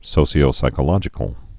(sōsē-ō-sīkə-lŏjĭ-kəl, -shē-)